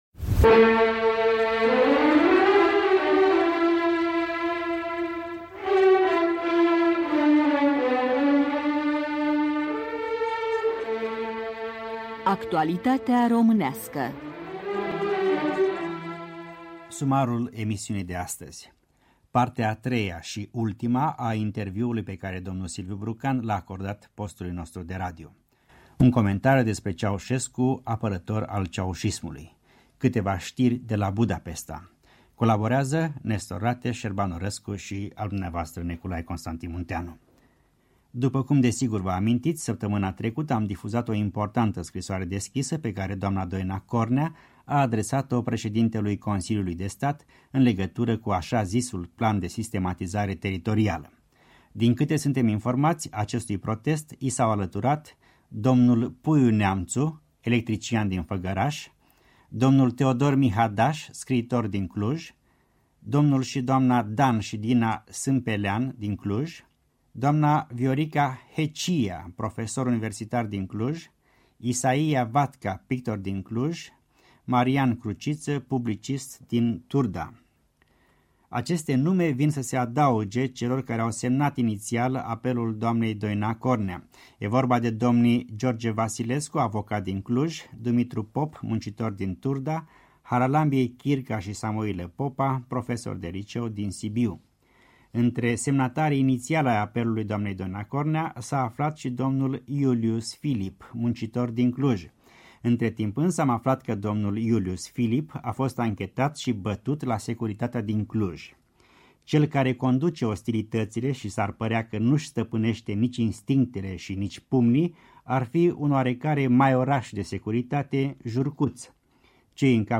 Actualitatea românească: Silviu Brucan la microfonul Europei Libere (III)